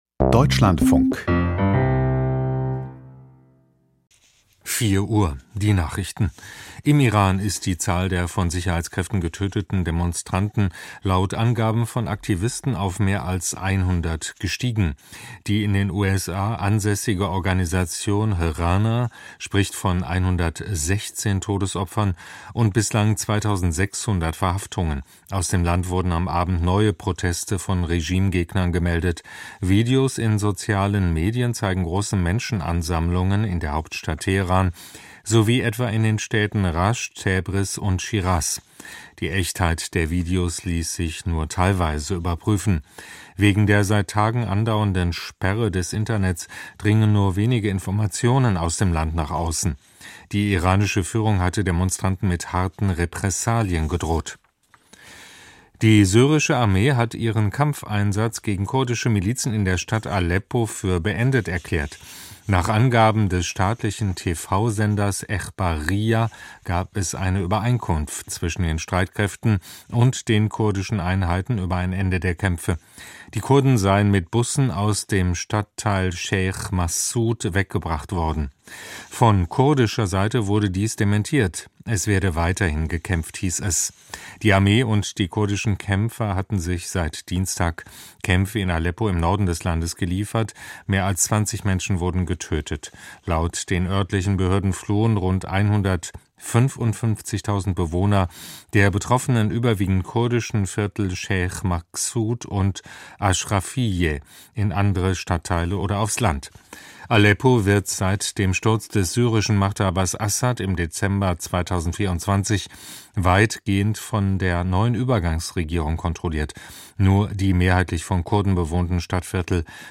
Die Nachrichten vom 11.01.2026, 04:00 Uhr